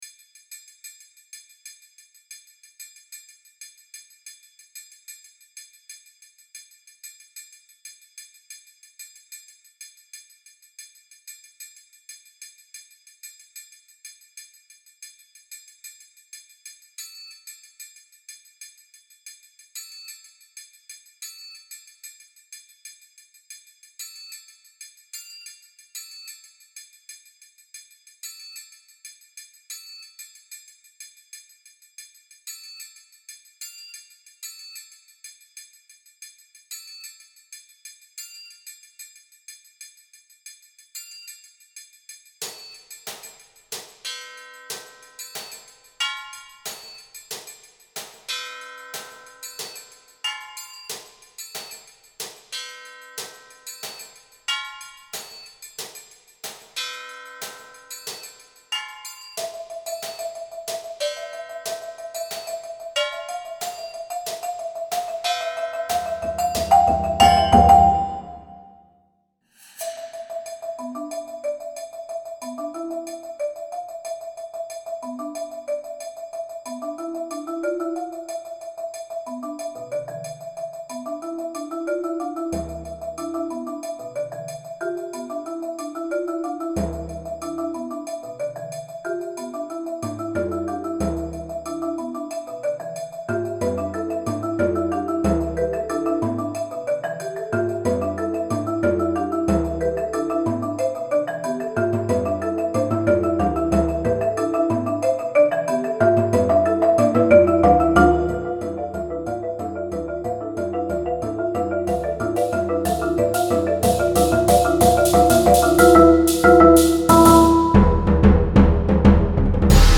Voicing: Percussion Quintet